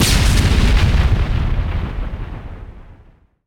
grenadeexplode.ogg